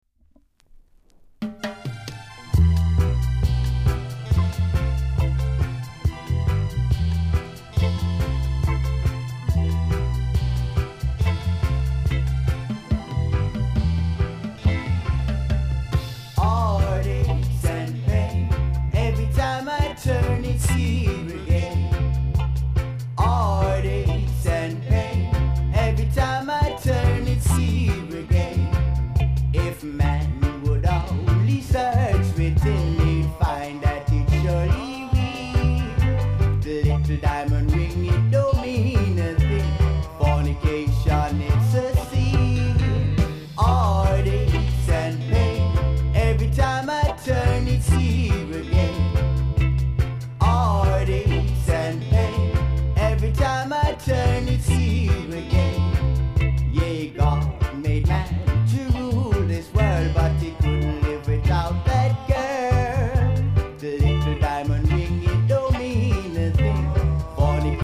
※多少小さなノイズはありますが概ね良好です。
コメント RARE!!後半にINSTへ繋がります。